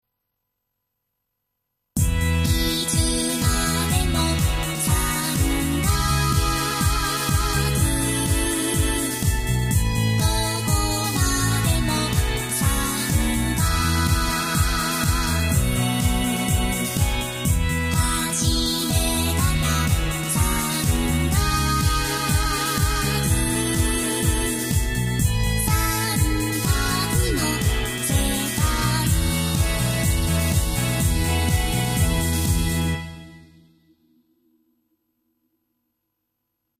最近作る習作は、結局ゴチャゴチャし過ぎているから微妙なんじゃないかと思い、リズムセクションのギターとキーボードを使わないで作るとどうなるか試してみた。
作っている途中に、ギターやキーボードの演奏も入れてみたりしたけれど、ないほうがゴチャゴチャしてなくて良かったので抜いた。
今回は、ストリングスの伴奏から作った。
ストリングスの第一バイオリンは当初旋律的な伴奏をさせていたが、音楽的に複雑というか、乱雑な感じになってしまったので低音楽器と合わせて和音を作るようにした。
スネアドラム、ハイハットでグルーヴが作れるように、強弱や発音タイミングに注意して作ってみた。
ボーカロイドは、やっぱり初音ミクが一番使いやすい。